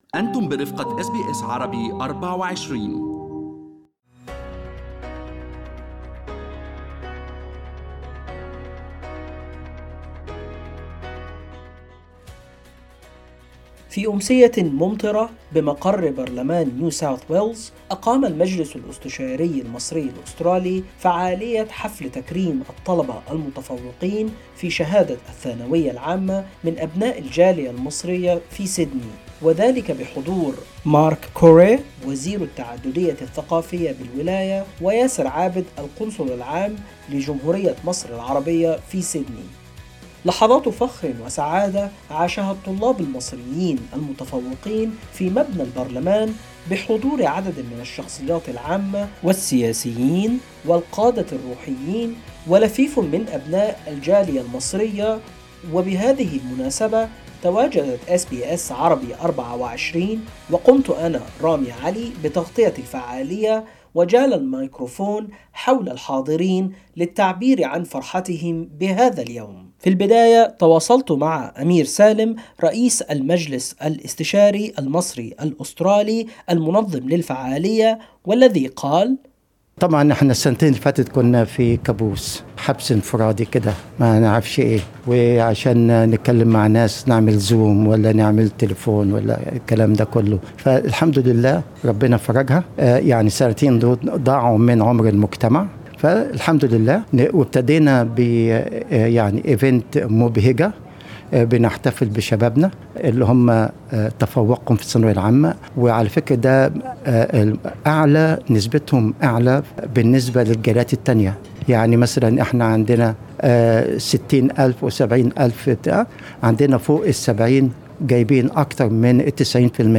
في أمسية ممطرة بمقر برلمان نيو ساوث ويلز، أقام المجلس الاستشاري المصري الأسترالي فعالية حفل تكريم الطلبة المتفوقين في الثانوية العامة من أبناء الجالية المصرية في سيدني، بحضور مارك كوري وزير التعددية الثقافية بالولاية وياسر عابد القنصل العام لمصر في سيدني.